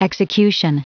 Prononciation du mot execution en anglais (fichier audio)
Prononciation du mot : execution